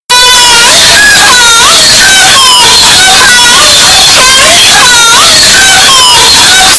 gmi2 earrape
gmi2-earrape.mp3